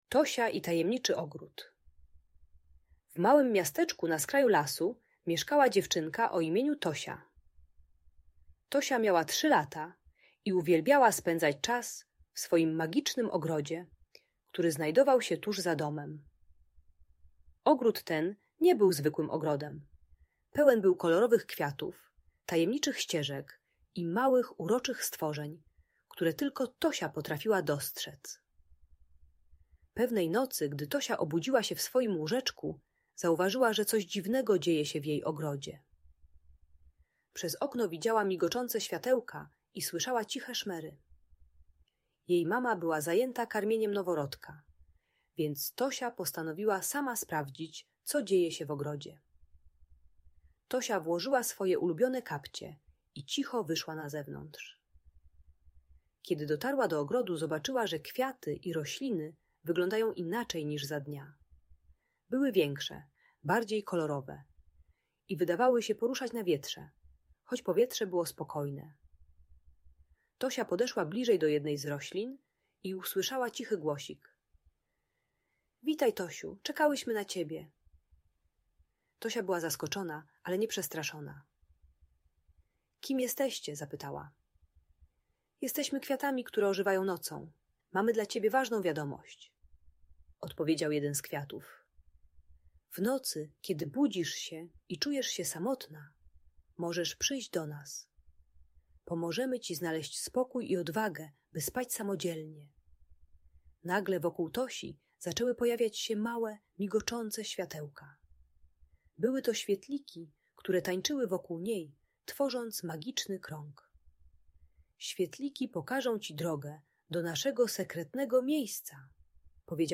Tosia i Tajemniczy Ogród - Audiobajka